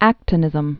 (ăktə-nĭzəm)